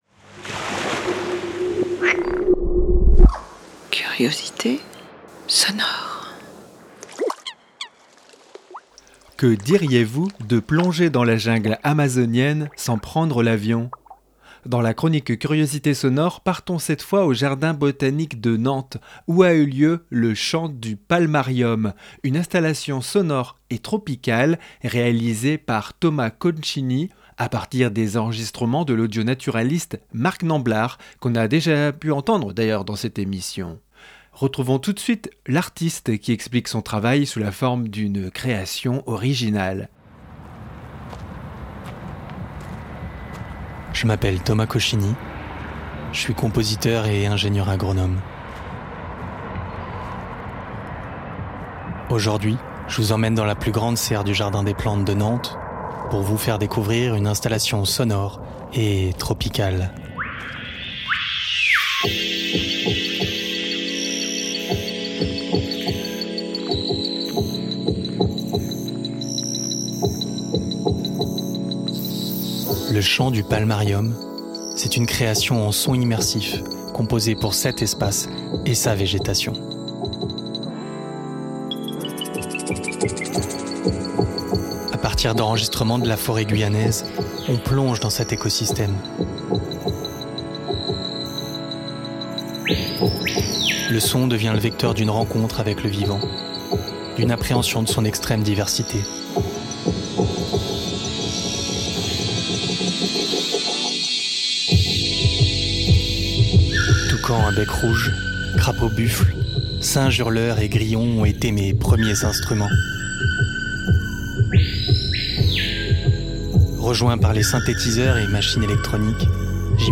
musiques électroniques
Réalisé à partir de feuillages séchés et de bruit de graines, qu’il récolte lui-même, ce titre est une ode au monde organique, mâtiné d’une nostalgie dansante.